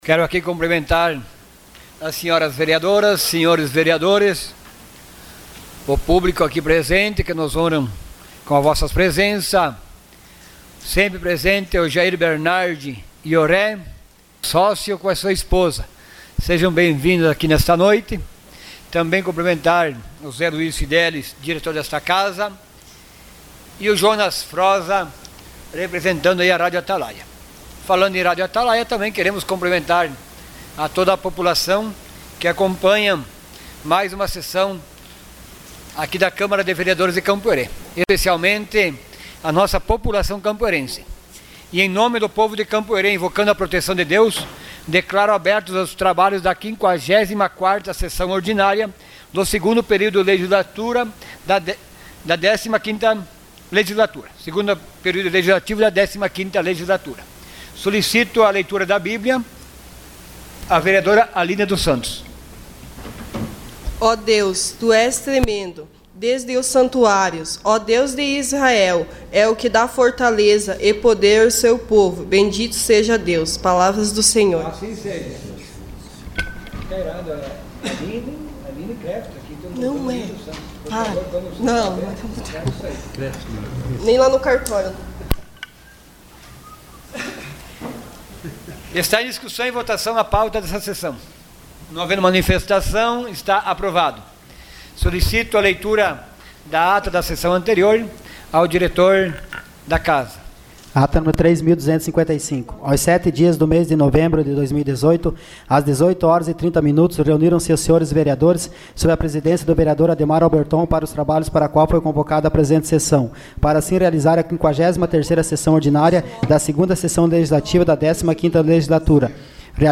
Sessão Ordinária dia 12 de novembro de 2018.